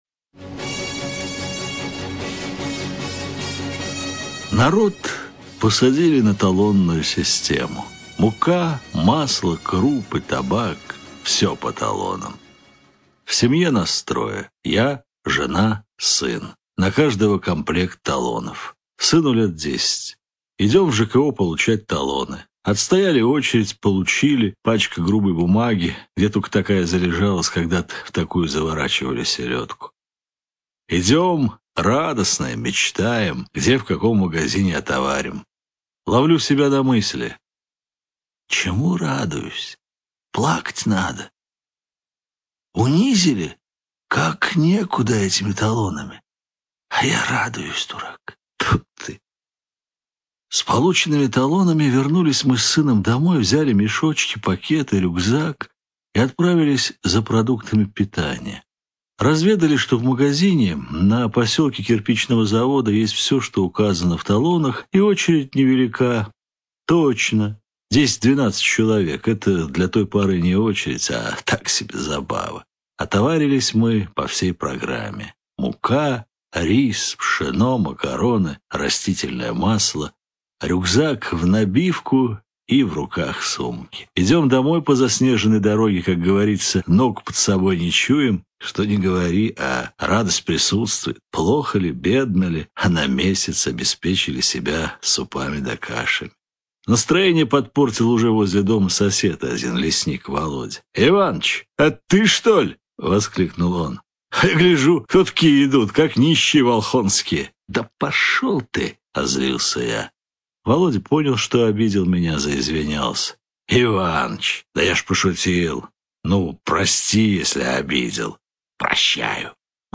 Аудиокнига рассказов Виктора Герасина — это сборник историй о наших современниках и о людях советской эпохи: жителях сел и деревень, простых людях с непростой судьбой.